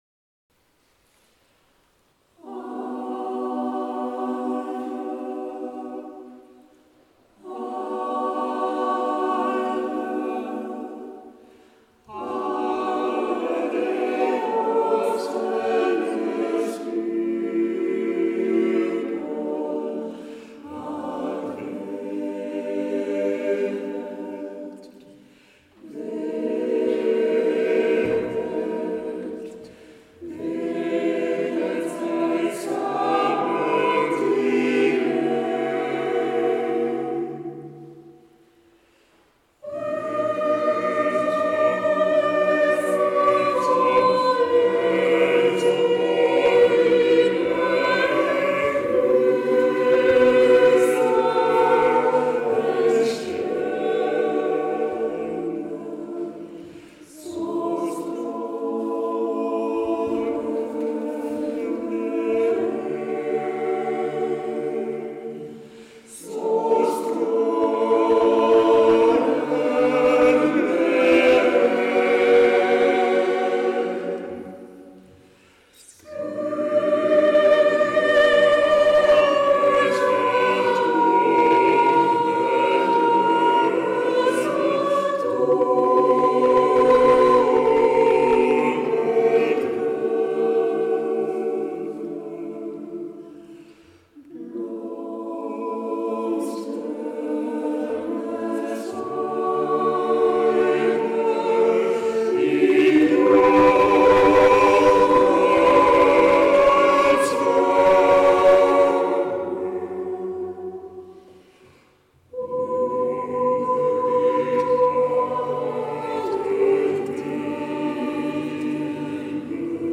Névache
Rando raquettes et chant choral
Musique chorale du Nord